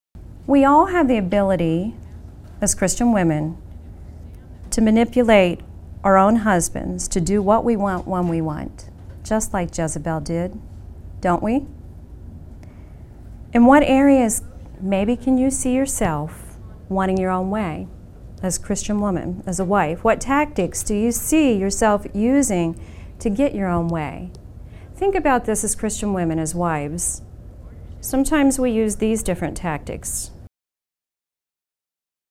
We, as Christian women, can fall into the same trap by placing undue emphasis on external beauty, which will fade. This workshop is designed to call us back to placing value on our internal beauty, the "hidden man of the heart," which is not corruptible, and to teach the downfalls and blessings of both sides.